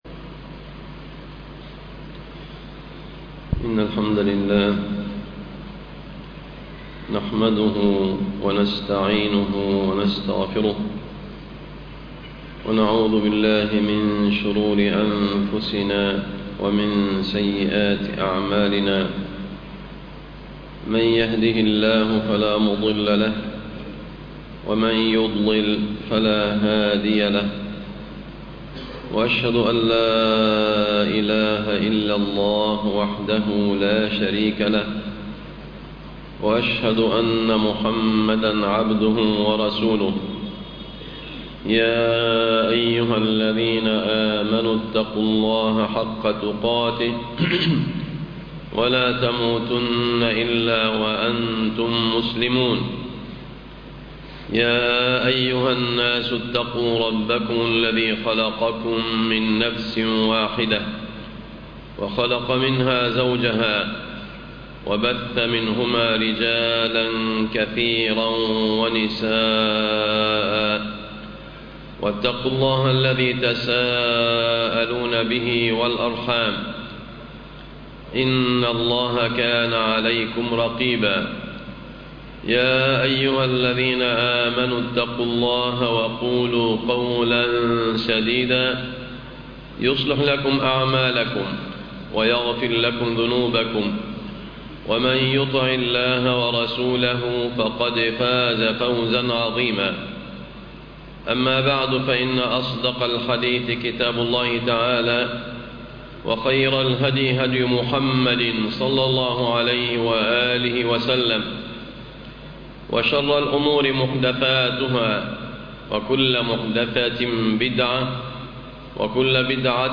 سورة الفجر دروس وعبر - خطب الجمعة